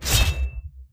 Melee Sword Sounds
Melee Weapon Attack 20.wav